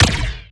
LASRGun_Particle Compressor Fire_01.wav